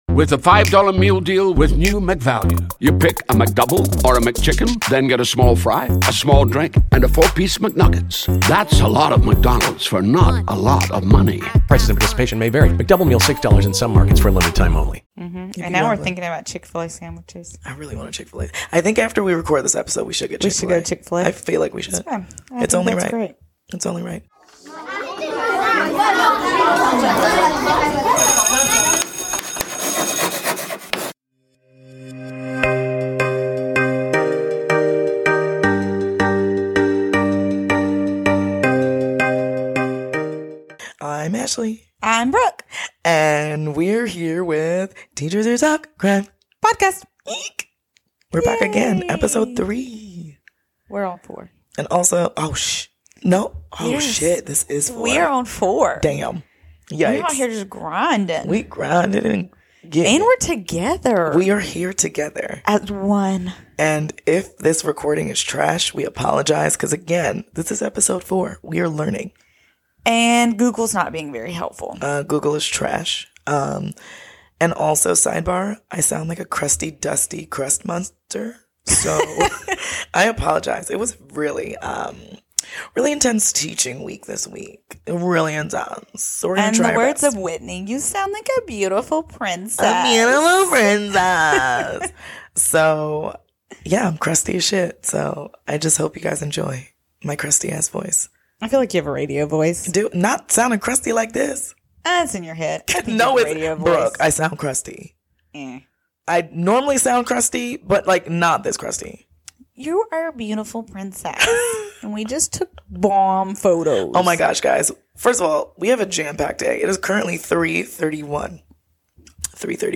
This episode was recorded while we were together in Delaware for the weekend. Please bare with us as we jump between recording remotely and recording in person.
We know our sound quality can vary from episode to episode, but again we are learning.